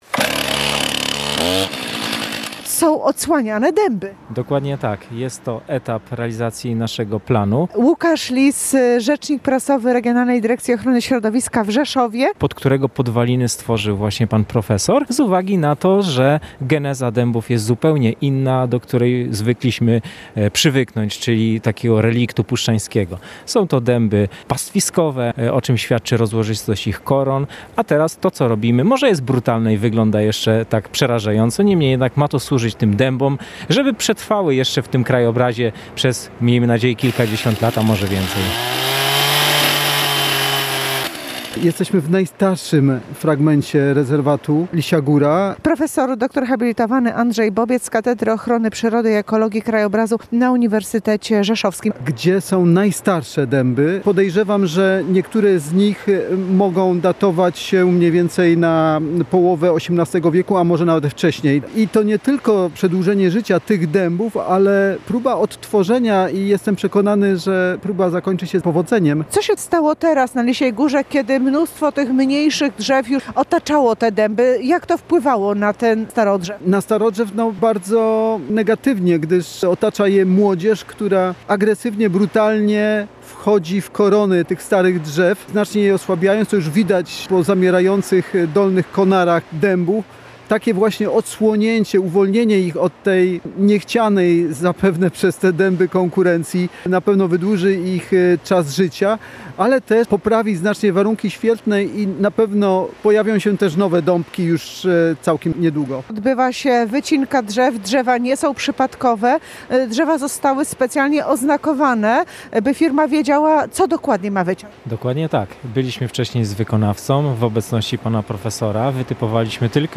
Wycinka na Lisiej Górze w Rzeszowie [ZDJĘCIA] • Relacje reporterskie • Polskie Radio Rzeszów